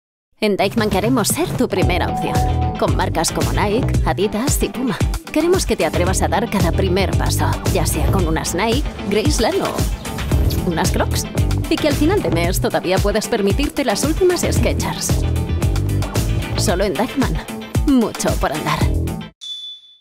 Neumann U87 + Studiobricks.
kastilisch
Sprechprobe: Werbung (Muttersprache):